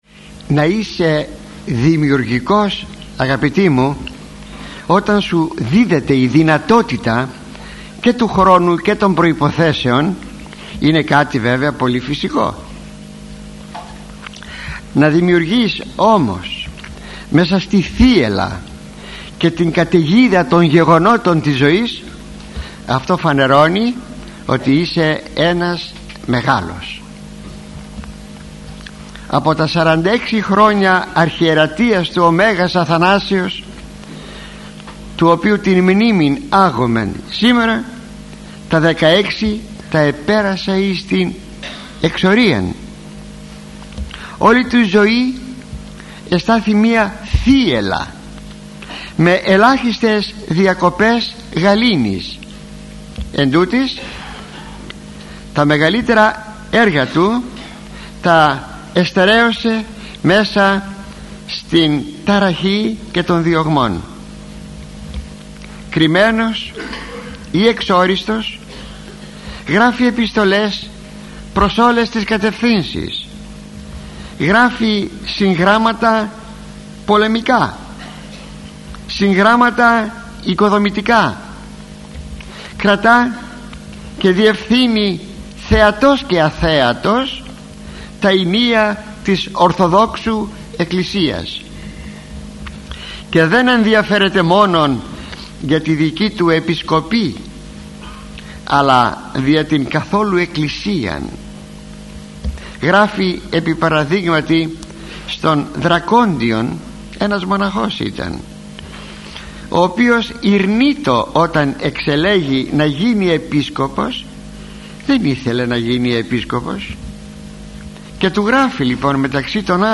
ηχογραφημένη ομιλία του Αρχιμ.